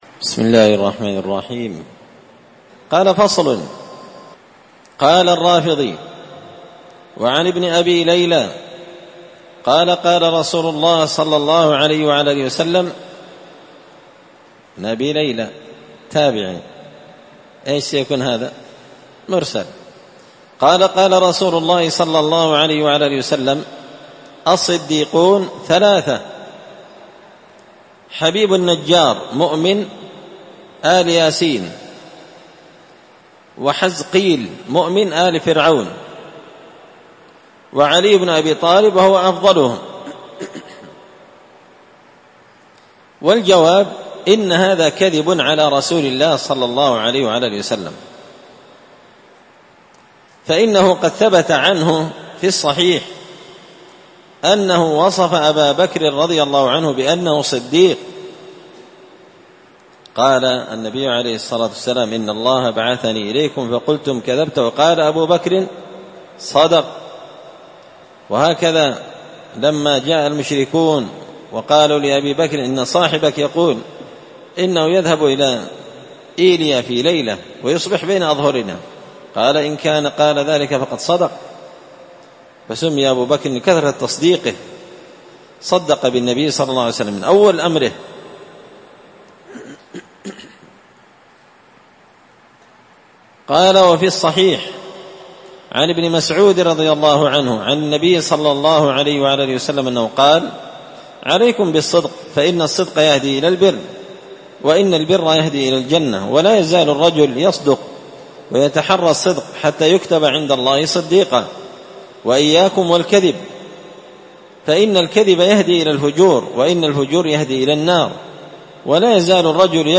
الأربعاء 11 ذو القعدة 1444 هــــ | الدروس، دروس الردود، مختصر منهاج السنة النبوية لشيخ الإسلام ابن تيمية | شارك بتعليقك | 12 المشاهدات
مسجد الفرقان قشن_المهرة_اليمن